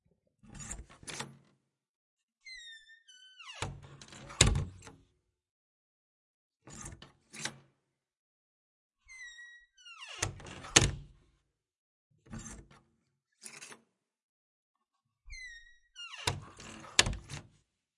开门
描述：我需要一扇很重的大门来迅速滑开。
标签： 关闭 关闭 吱吱作响的 木制的 推拉 滑板 快速 开放
声道立体声